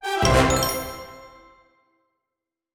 Quest Complete.wav